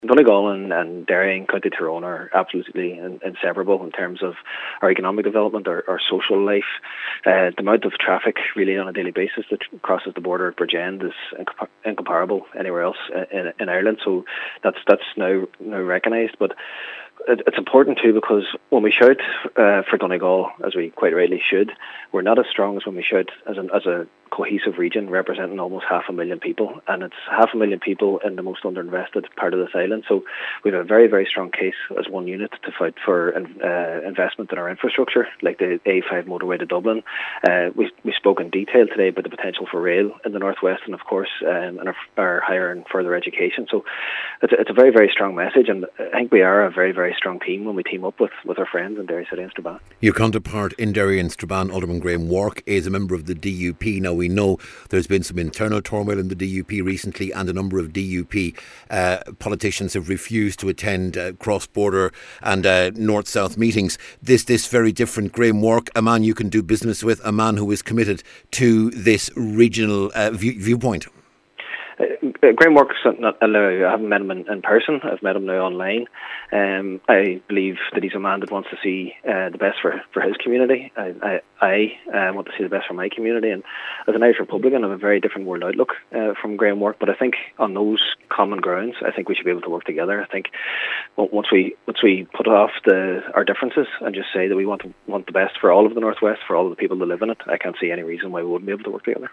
Cllr Jack Murray says it was a significant meeting, and demonstrated the strength the two councils have when they work together for the region: